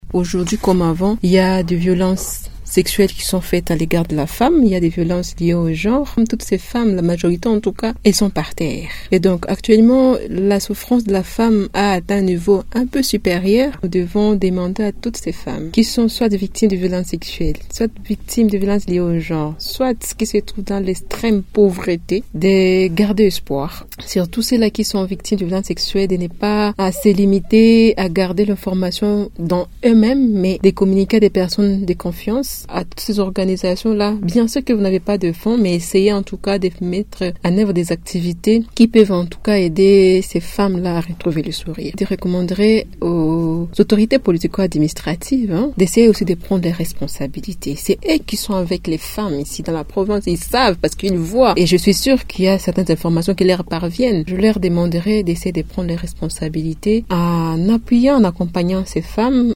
dans une interview avec Radio Maendeleo.